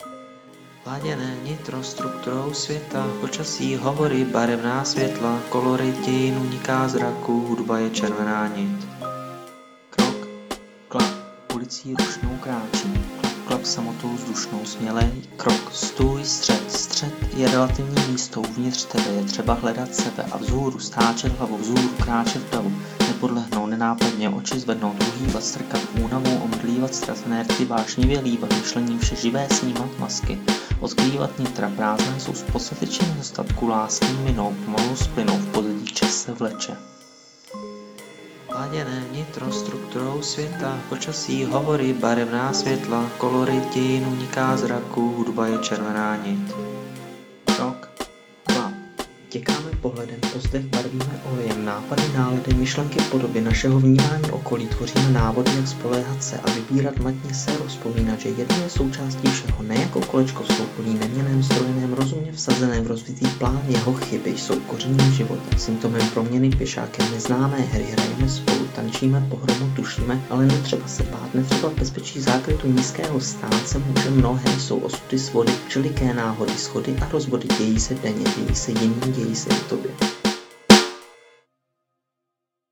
Pokus o jakousi hudební slam poetry ve stylu Kate Tempest a záměrně v češtině - moc se to teda nepovedlo, ale práci to dalo a formu to má.